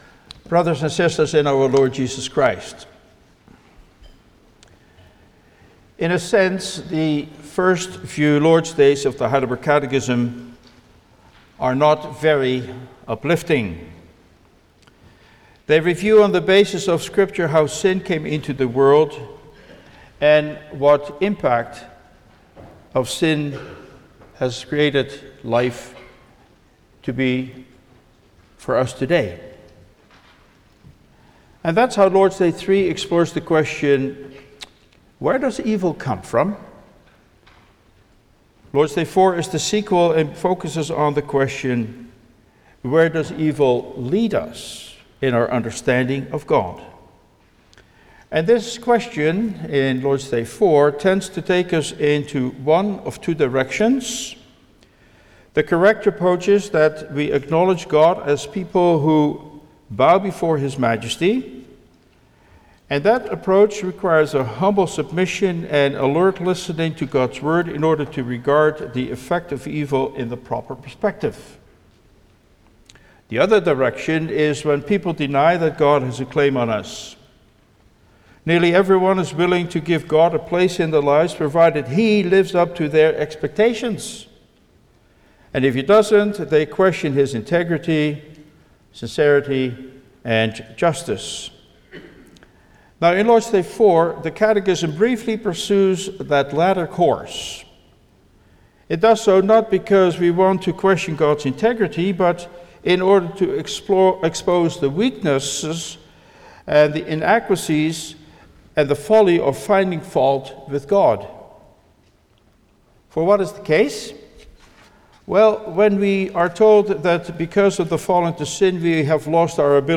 Service Type: Sunday afternoon
07-Sermon.mp3